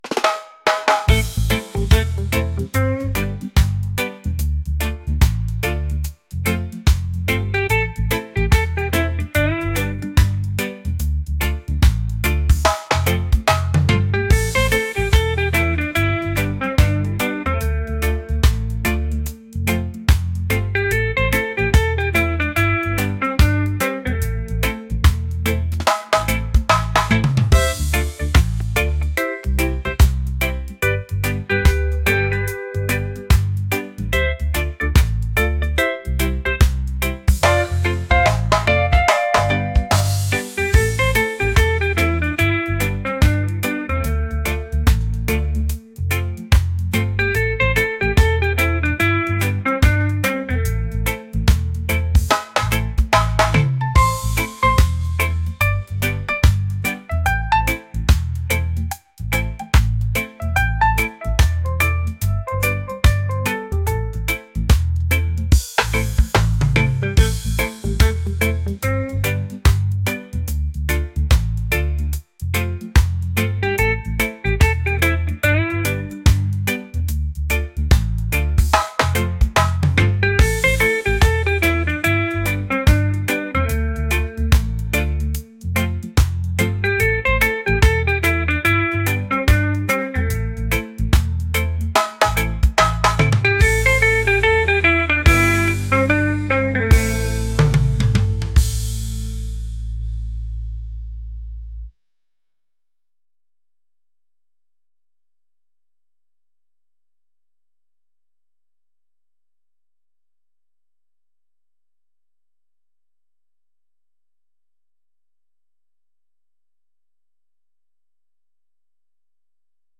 laid-back | groovy | reggae